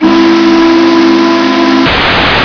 AMERICAN WHISTLES
hancock3chime.wav